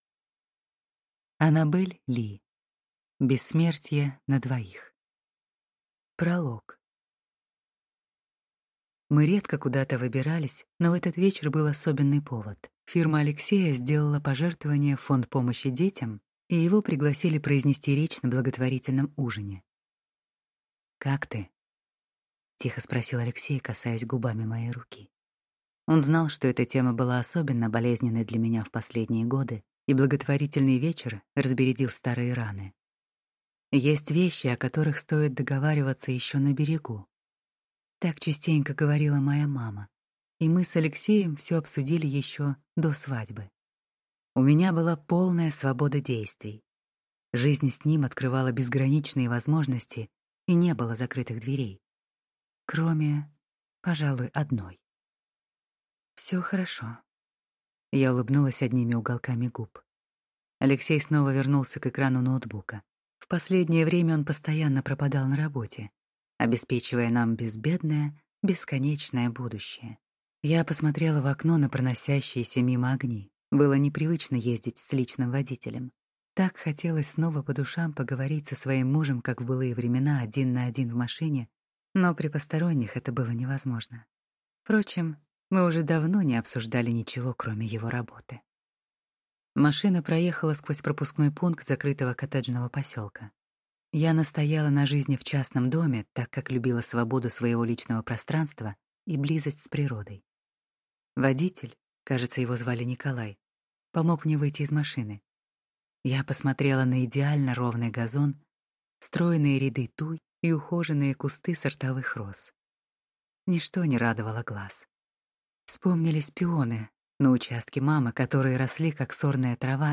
Аудиокнига Бессмертие на двоих | Библиотека аудиокниг